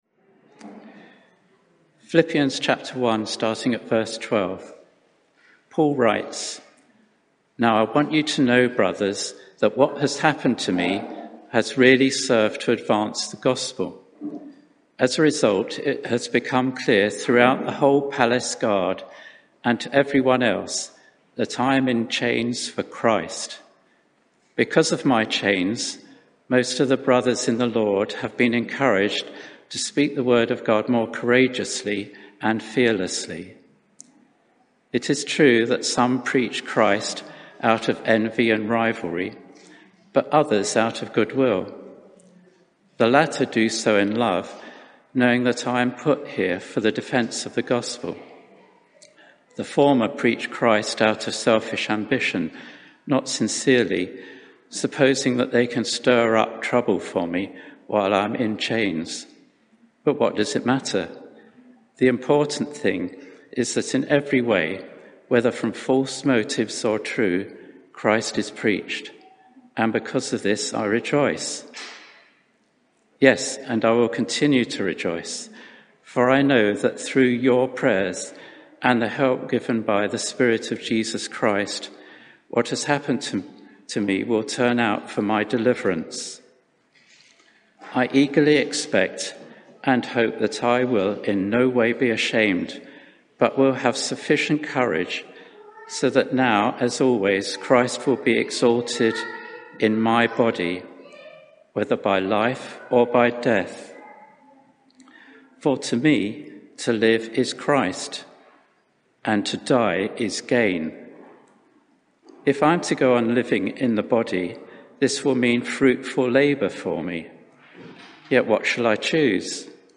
Media for 11am Service on Sun 25th Apr 2021 11:00 Speaker
Sermon (audio)